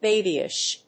音節ba･by･ish発音記号・読み方béɪbiɪʃ
発音記号
• / ‐biɪʃ(米国英語)
• / ˈbeɪbi:ɪʃ(英国英語)